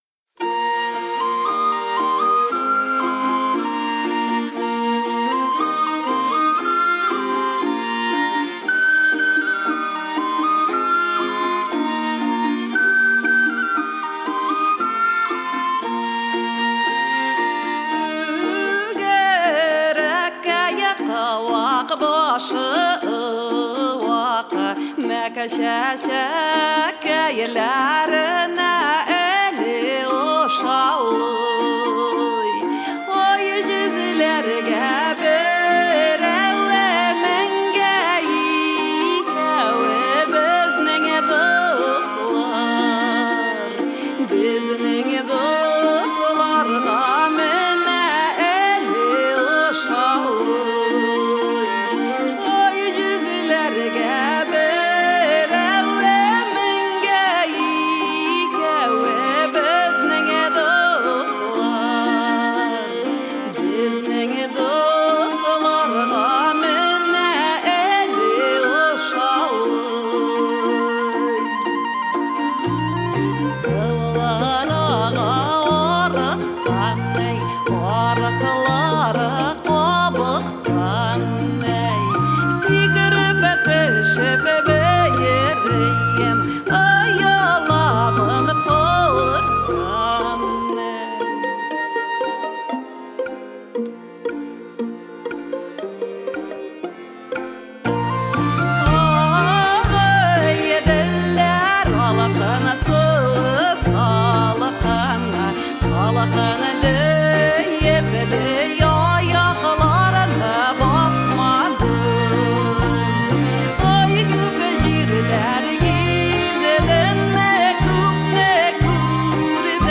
Татарскую народную вам в ленту :Dle76: Оркестр + вокал:Dle8: